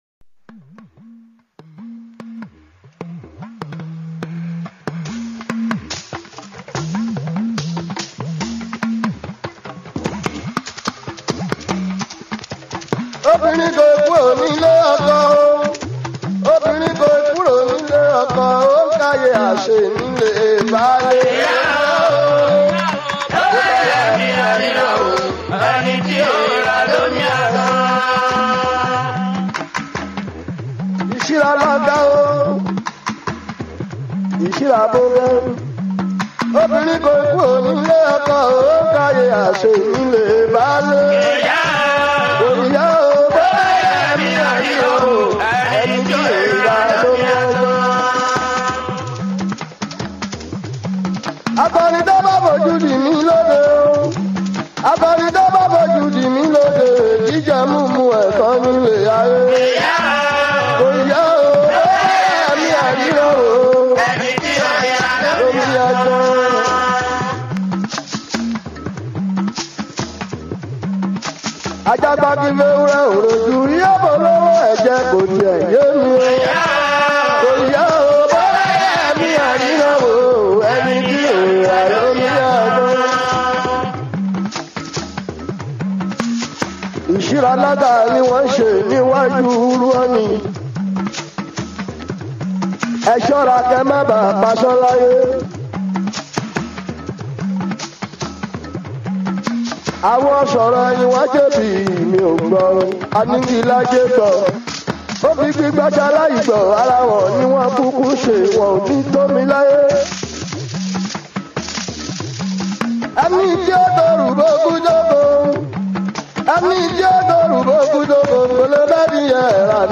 Yoruba Fuji song
Fuji song